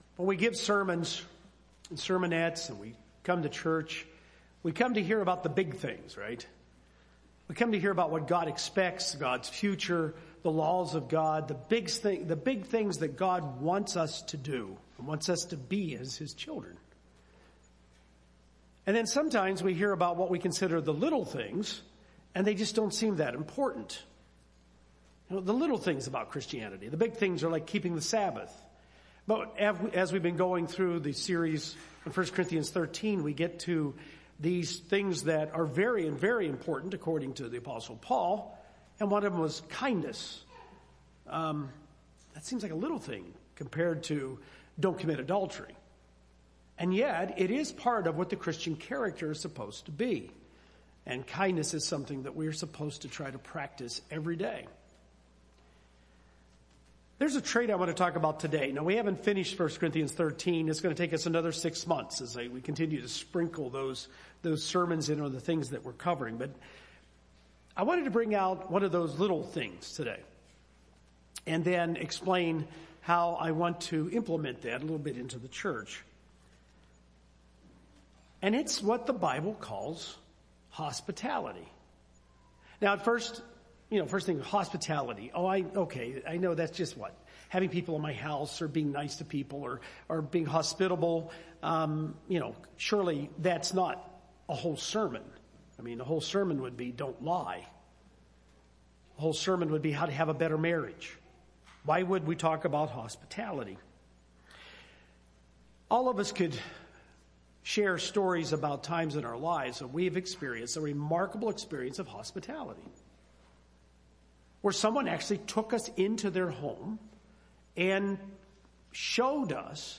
Hospitality is a trait all Christians should exhibit. This sermon looks at some relevant passages from the Bible about hospitality, and encourages local members to make plans to host one another in their homes.